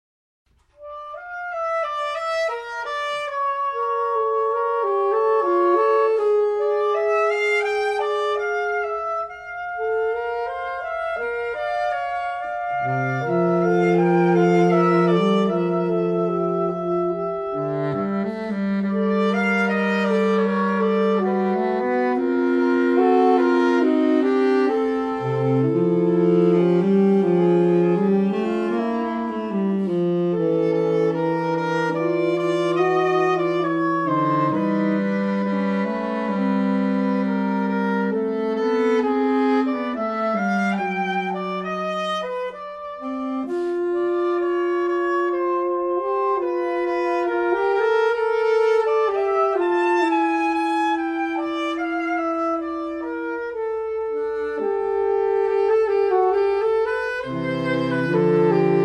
Saxophone Quartet